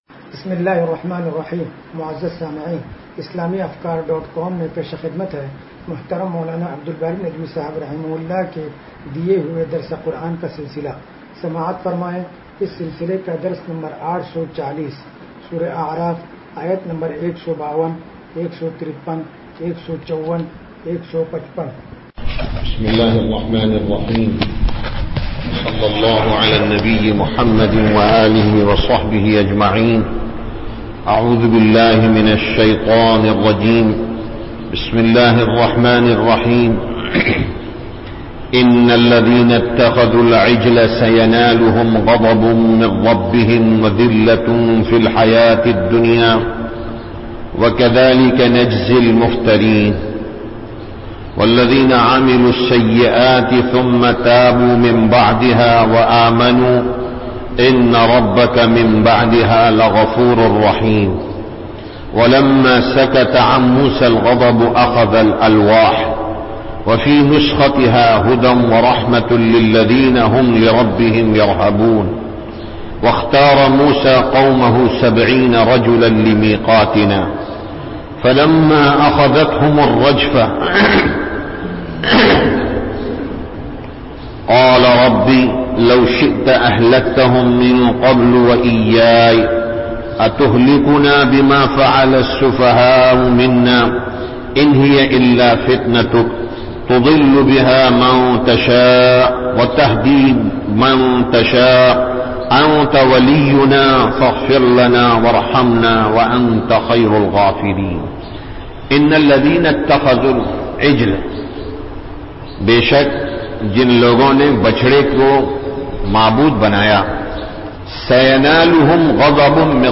درس قرآن نمبر 0840